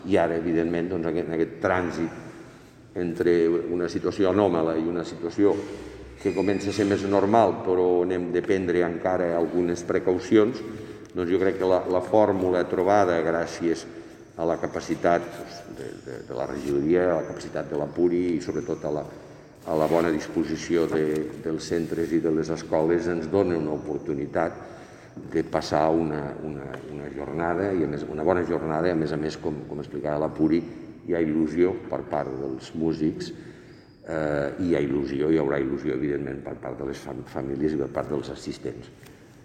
tall-de-veu-del-paer-en-cap-miquel-pueyo-sobre-la-xiv-festa-de-la-musica